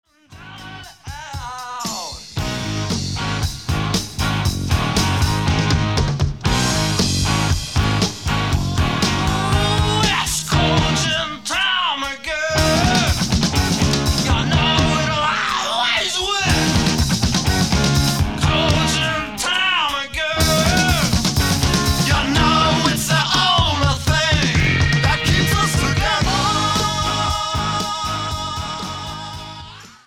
Recorded Location:Bell Sound Studios, New York City
Genre:Hard Rock, Heavy Metal